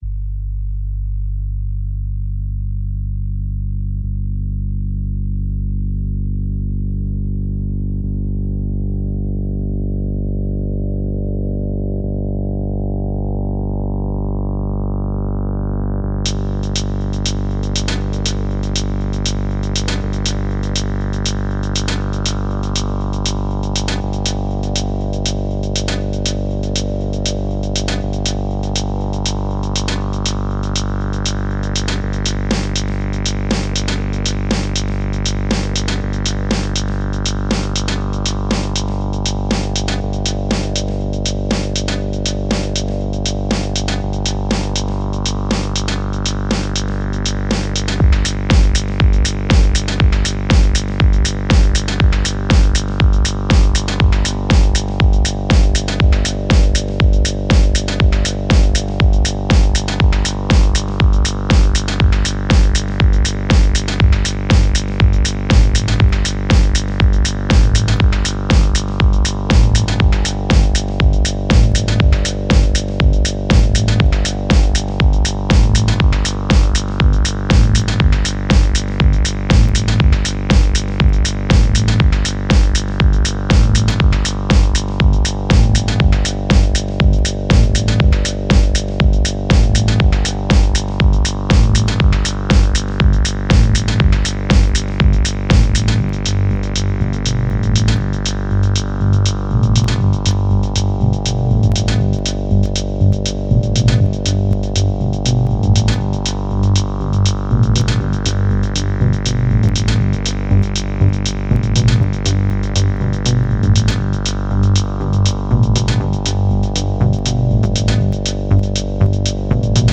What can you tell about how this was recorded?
Rarotonga International Flughafen auf den Cook Inseln